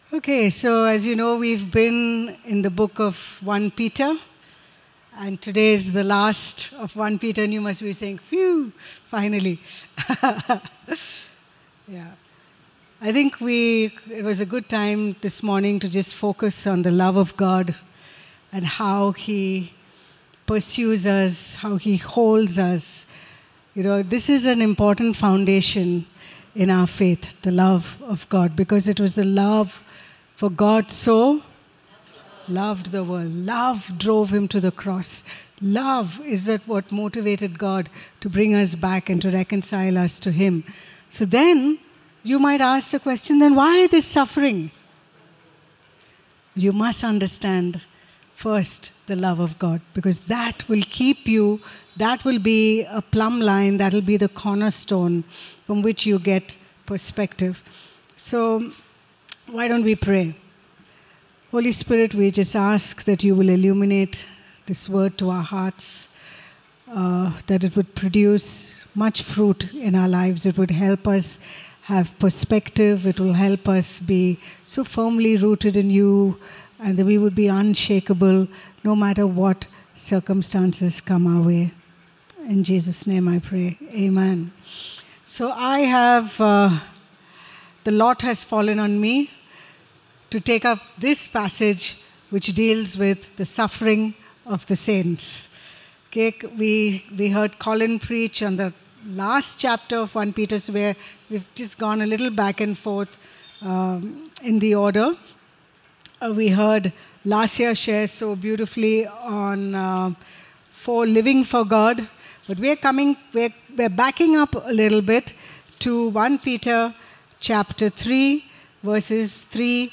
Be blessed as you listen to this sermon delivered at Word of Grace and do write a comment on how you have been blessed.